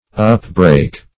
Search Result for " upbreak" : The Collaborative International Dictionary of English v.0.48: Upbreak \Up*break"\ ([u^]p*br[=a]k"), v. i. To break upwards; to force away or passage to the surface.